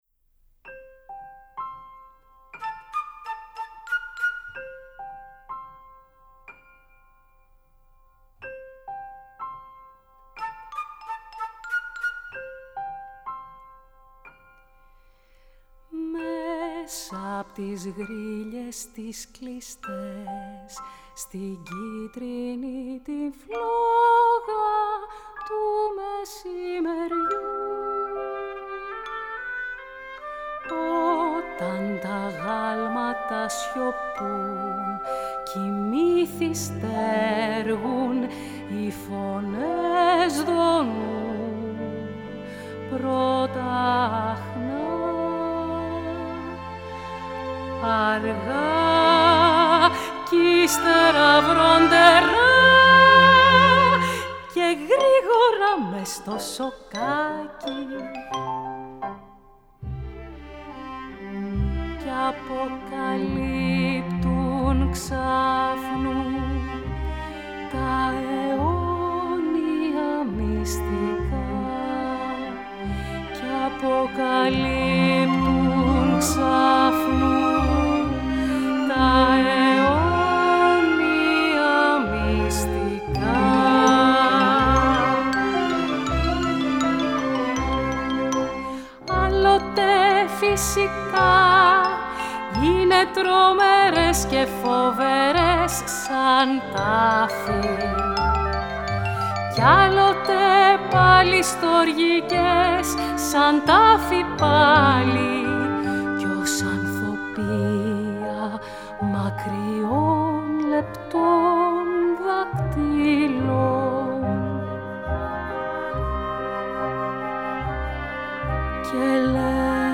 Ηχογράφηση στο STUDIO B, Παρασκευή 16 Οκτωβρίου 2020
Στο πιάνο ο συνθέτης